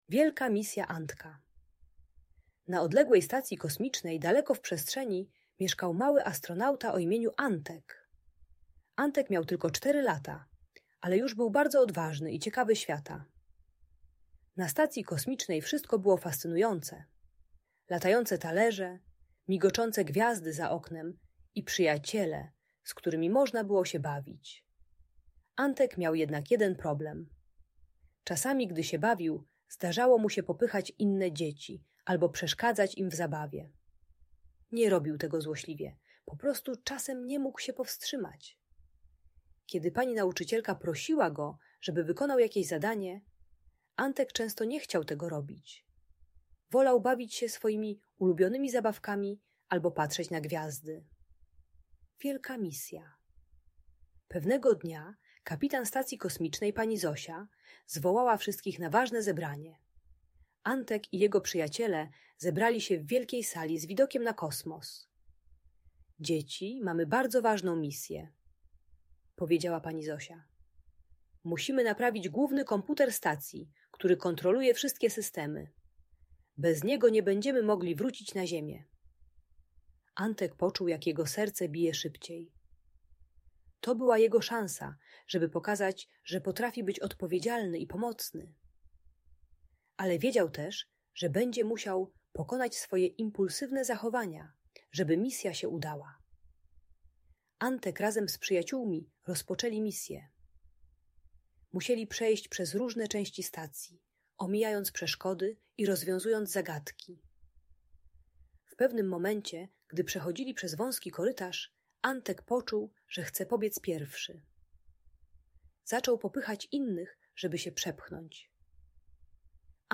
Wielka Misja Antka - Audiobajka